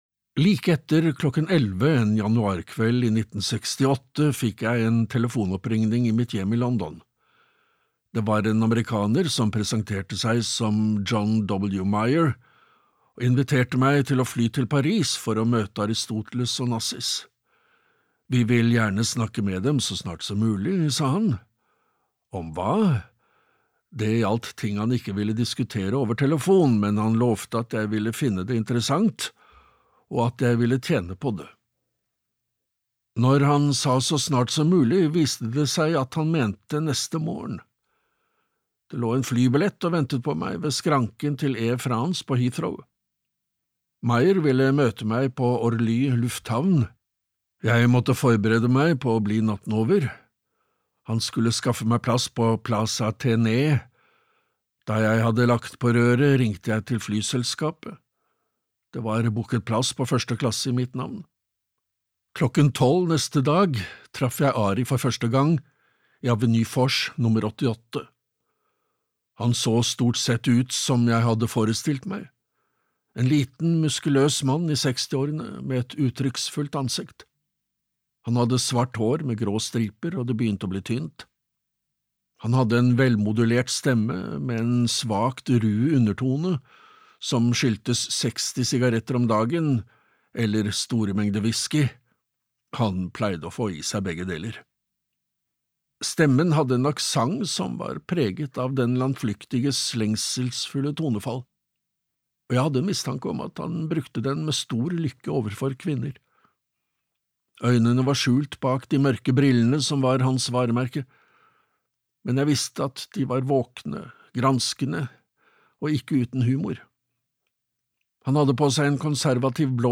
Aristotle Onassis - hans liv og levnet (lydbok) av Peter Evans